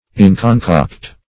Search Result for " inconcoct" : The Collaborative International Dictionary of English v.0.48: Inconcoct \In`con*coct"\, a. [L. pref. in- not + concoctus, p. p. of concoquere.